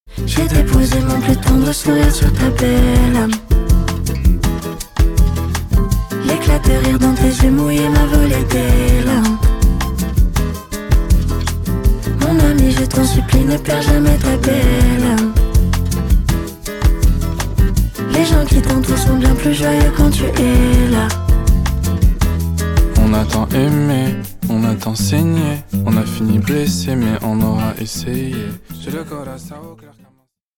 Refreshing, soft and smooth yet has a lot of character to it